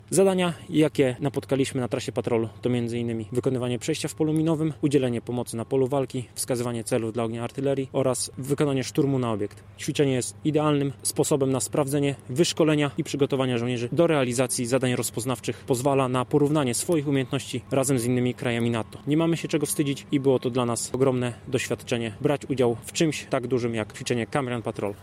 -To doskonała okazja, aby sprawdzić swoje przygotowanie do wykonywania zadań w nowym terenie – dodaje oficer: